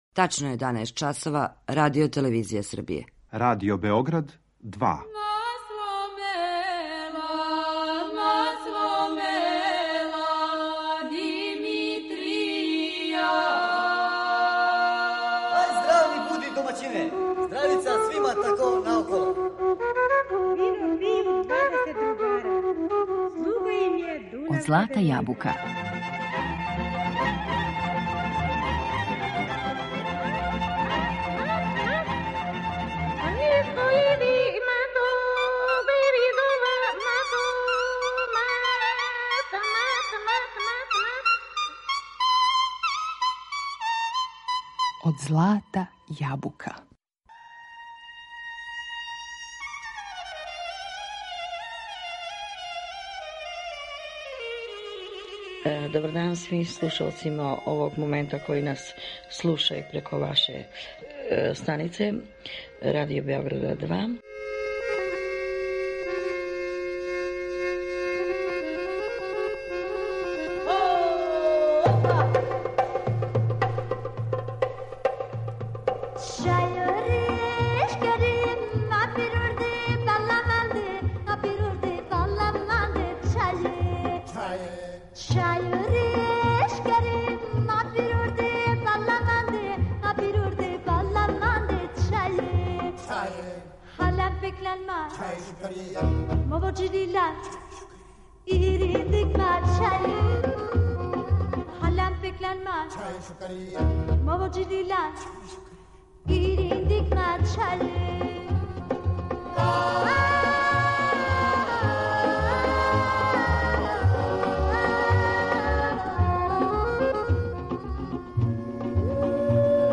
Поред сјајних, аутентичних песама, слушаћете и интервју који је са уметницом забележен 2008. године.